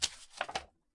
随机 "纸片穹顶在沙漠风中飘动
描述：在沙漠wind.flac拍打的纸页圆顶
标签： 圆顶 片材
声道立体声